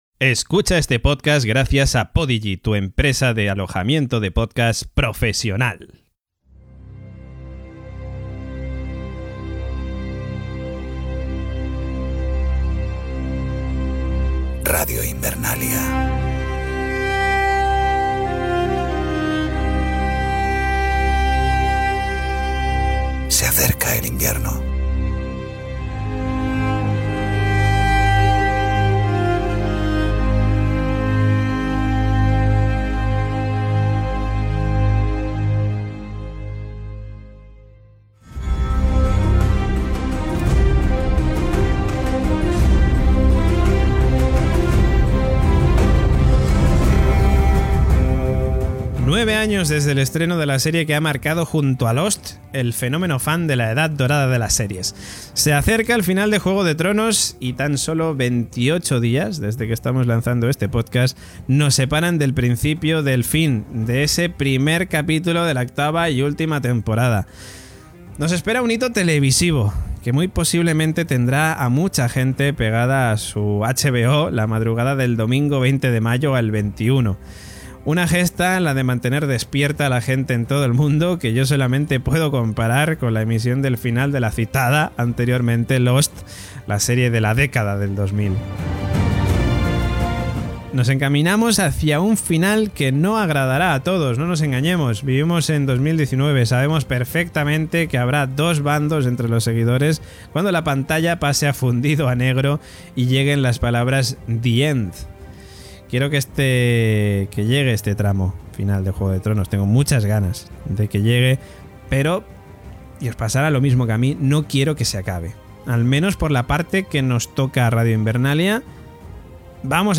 Hacemos reviews de cada capitulo emitido de la serie. Humor, spoilers, muchas teorías y desvarios varios.